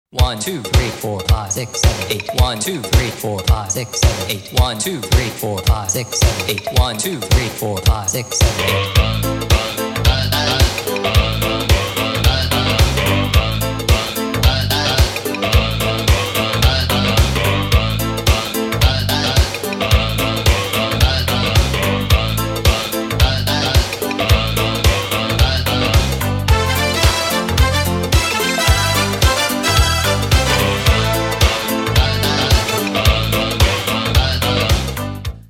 • Качество: 192, Stereo
поп
мужской голос
dance
EDM
ретро
итало-диско